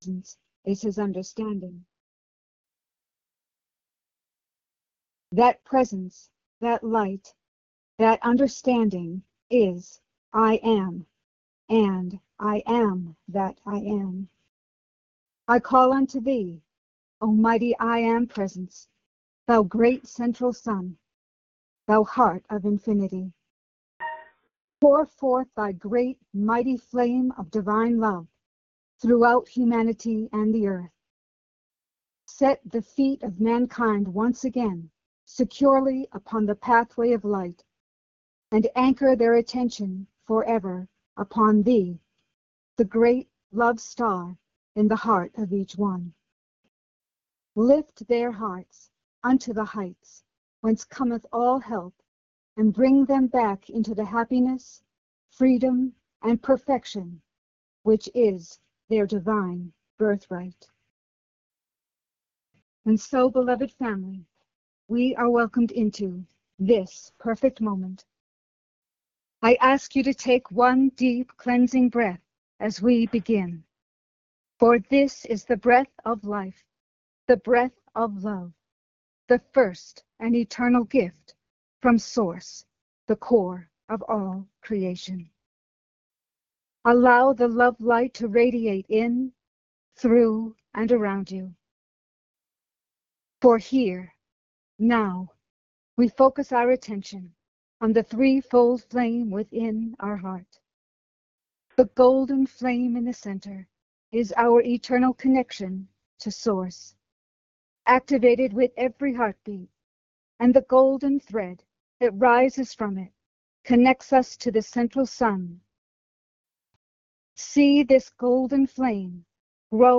Follow and join-in group meditation with Lord Sananda